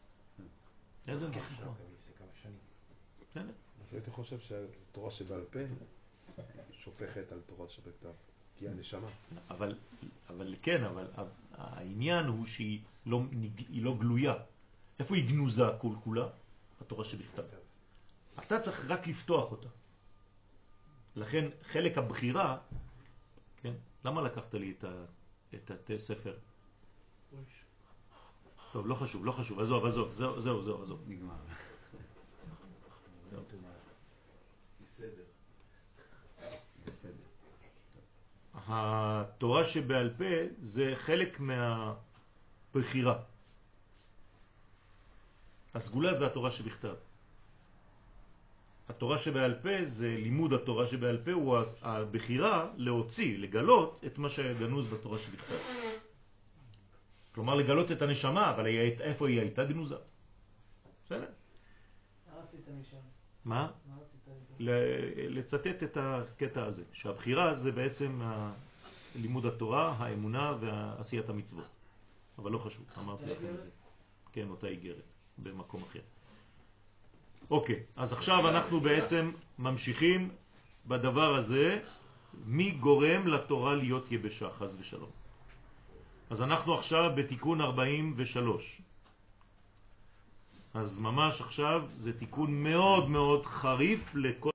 שיעור מ 04 פברואר 2019
שיעורים קצרים